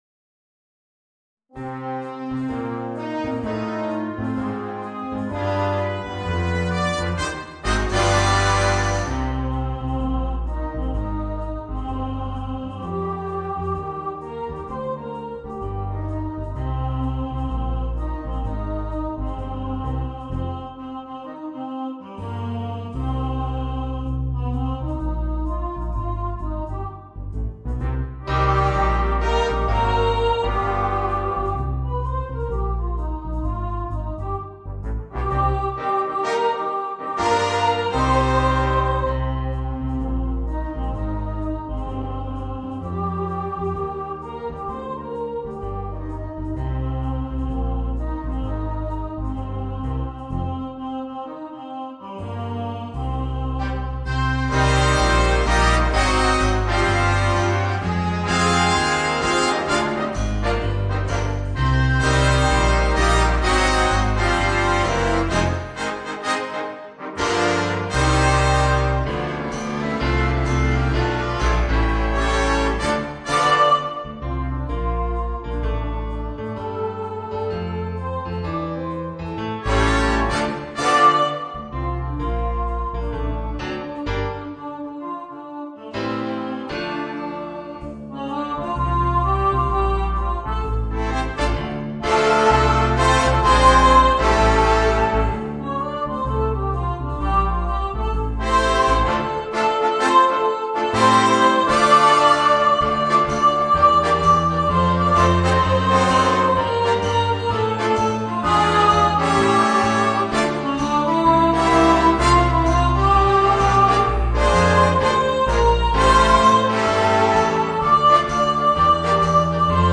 Orchestre à Vent